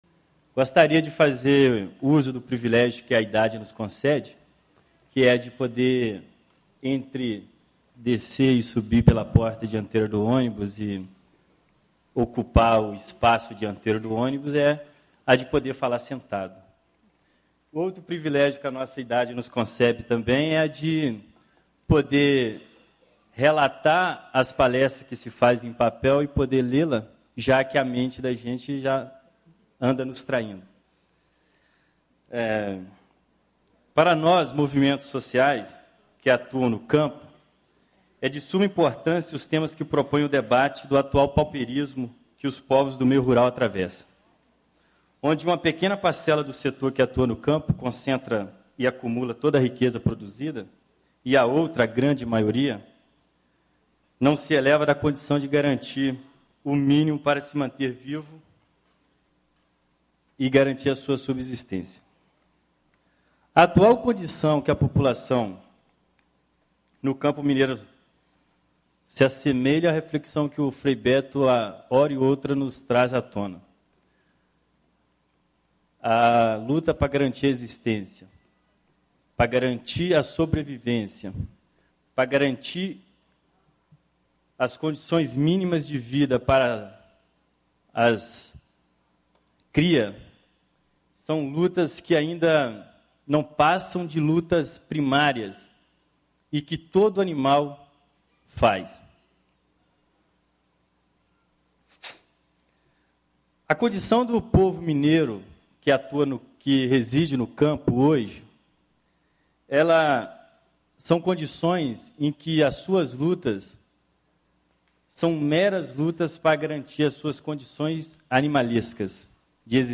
Ciclo de Debates Estratégias para Superação da Pobreza - Palestra: Pobreza no campo e as comunidades tradicionais: como enfrentar a pobreza nas áreas rurais de Minas Gerais? - Assembleia Legislativa de Minas Gerais
Discursos e Palestras